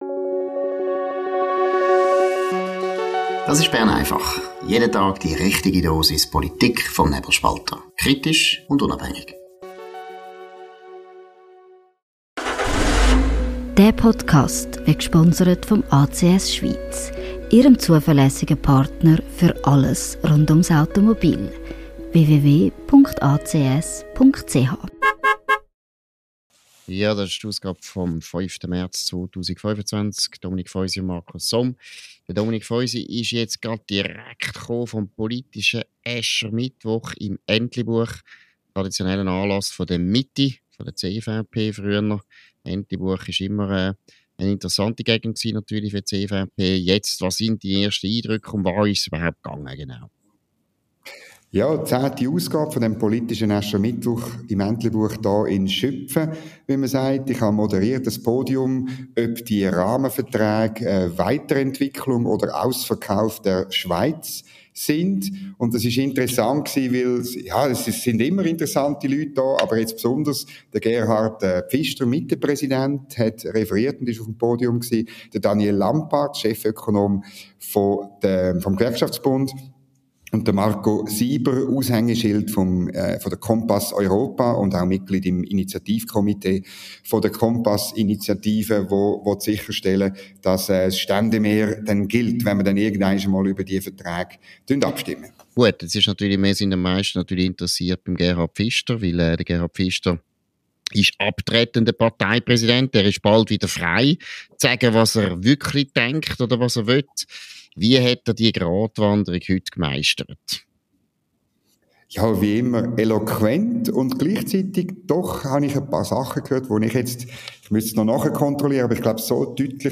News Talk